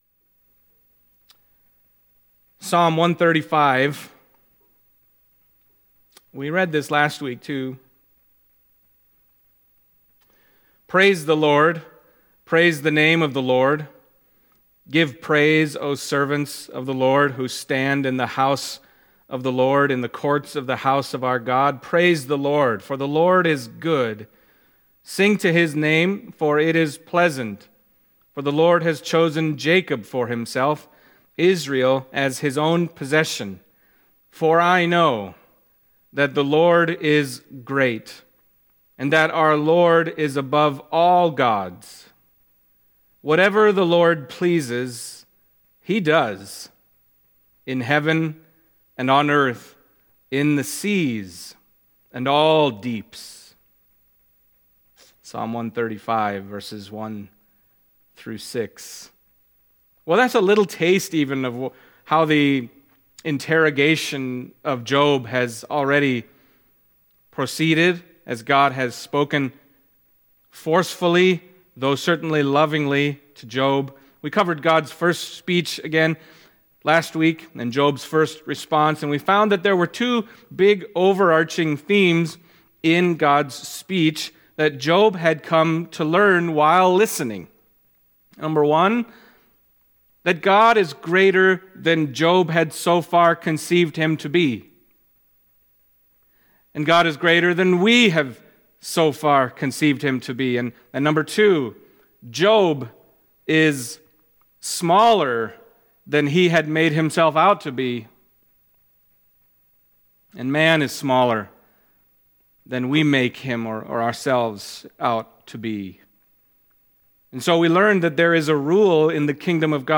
Job Passage: Job 40:6-42:6 Service Type: Sunday Morning Job 40:6-42:6 « The Words of God from the Whirlwind